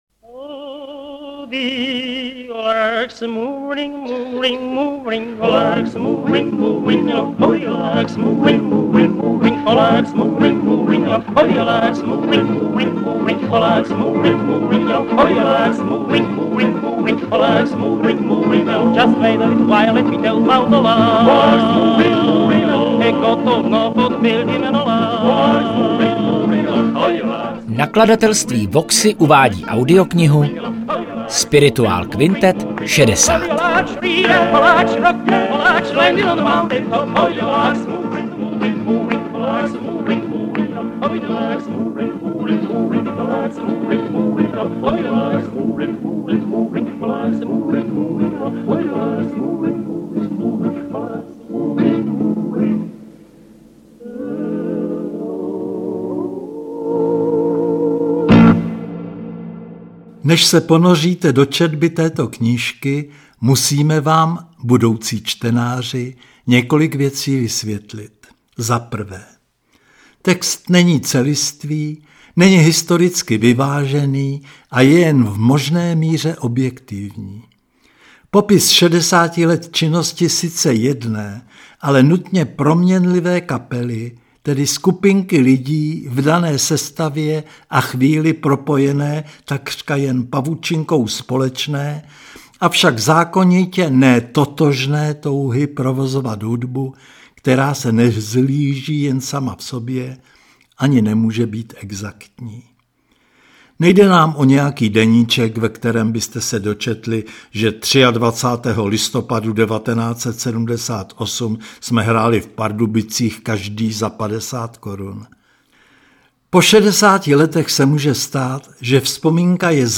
Interpreti:  Jiří Tichota, kol.